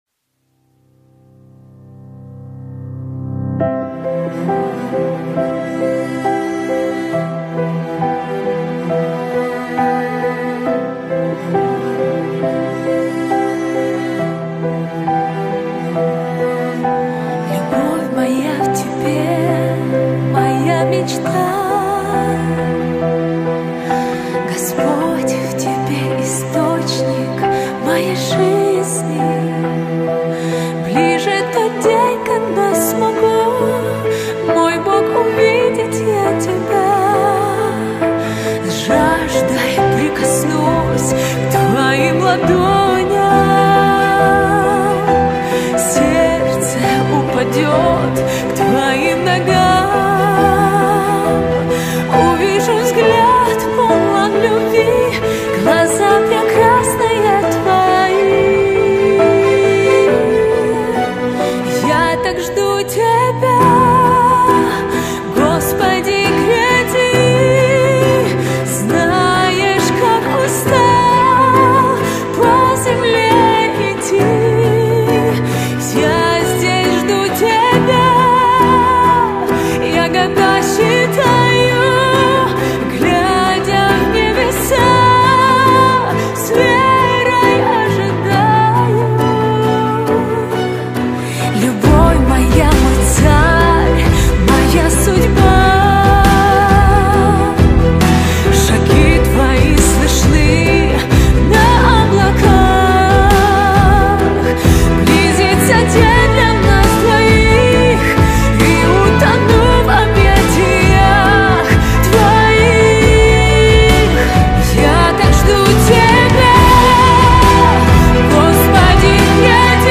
1303 просмотра 679 прослушиваний 148 скачиваний BPM: 136